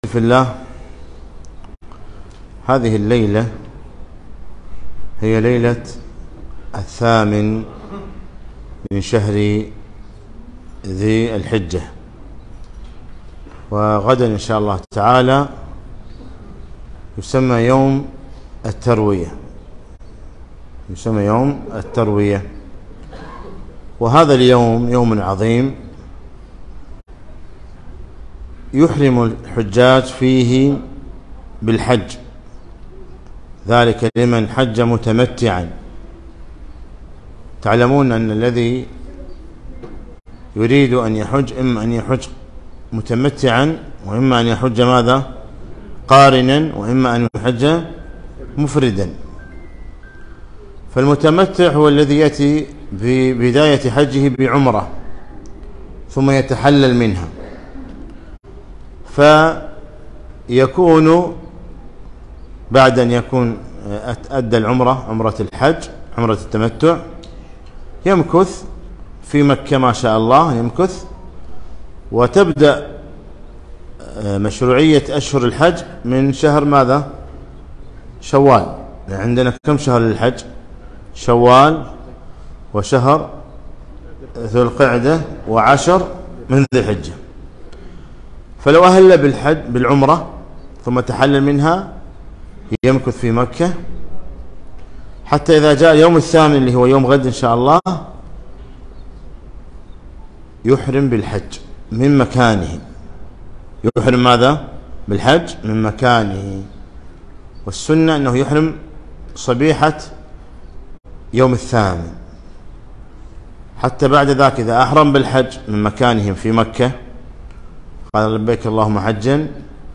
محاضرة - ( رحلة في رحاب الحج)